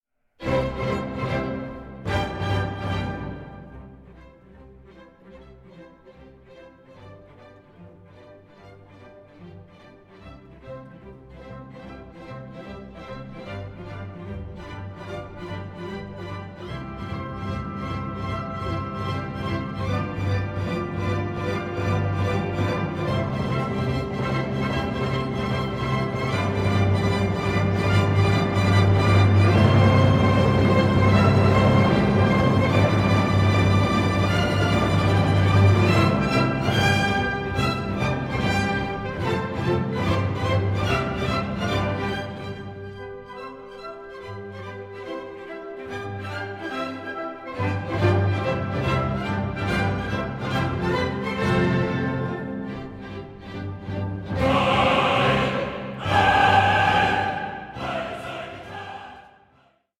in two studio sessions